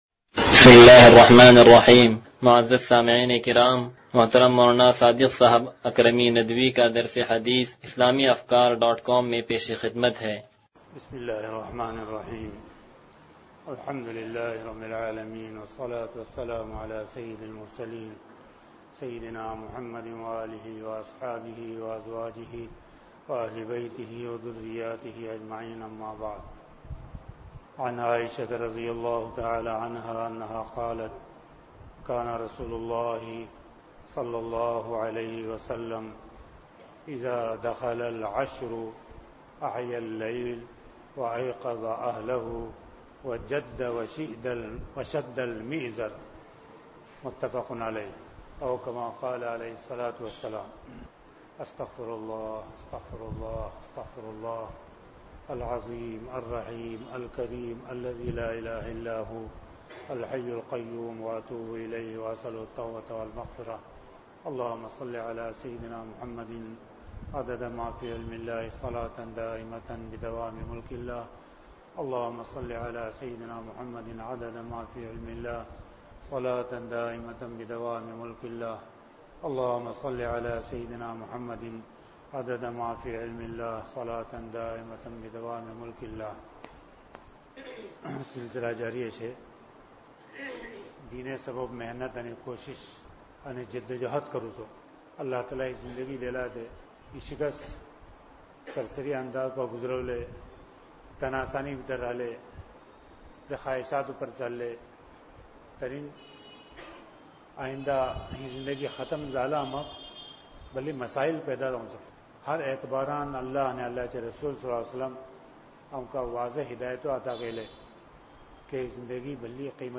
درس حدیث نمبر 0108